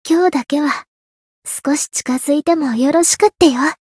灵魂潮汐-安德莉亚-七夕（摸头语音）.ogg